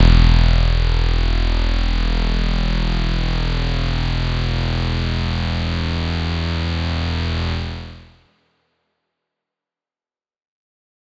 Bass_E_01.wav